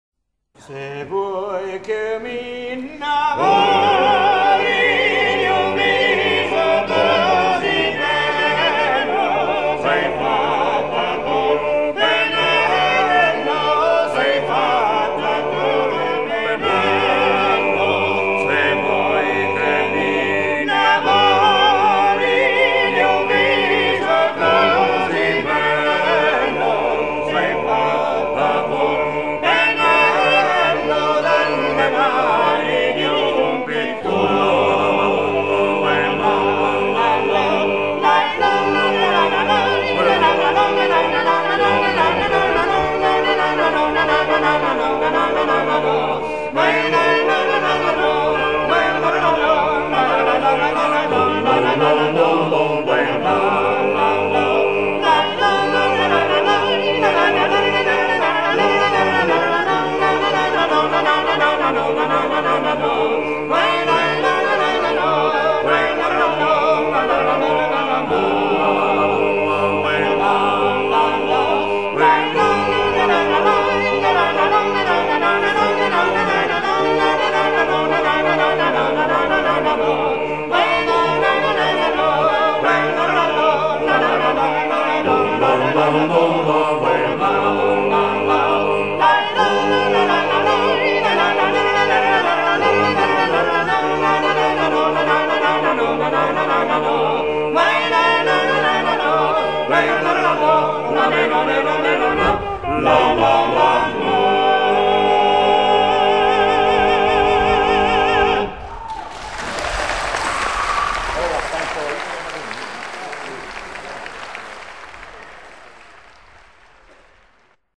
trallalero très ancien
Pièce musicale éditée